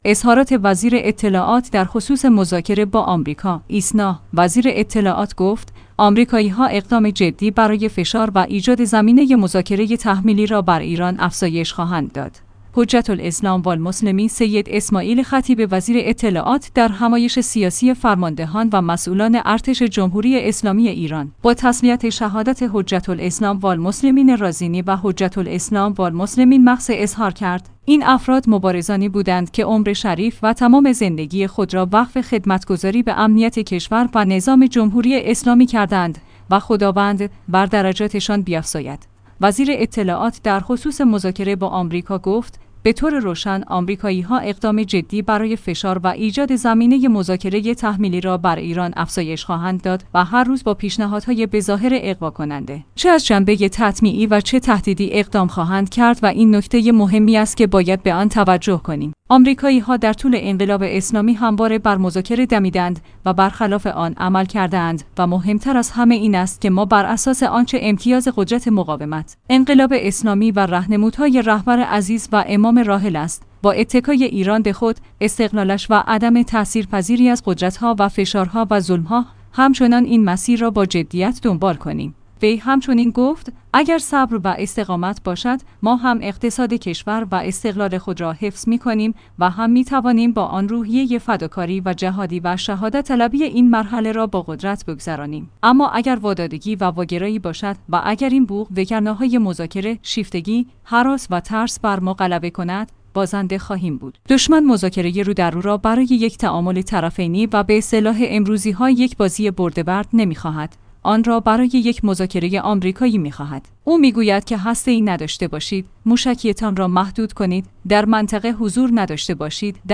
ایسنا/ وزیر اطلاعات گفت: آمریکایی‌ها اقدام جدی برای فشار و ایجاد زمینه مذاکره تحمیلی را بر ایران افزایش خواهند داد. حجت الاسلام والمسلمین سید اسماعیل خطیب وزیر اطلاعات در همایش سیاسی فرماندهان و مسئولان ارتش جمهوری اسلامی ایران، با تسلیت شهادت حجت‌الاسلام‌والمسلمین رازینی و حجت‌الاسلام‌والمسلمین مقی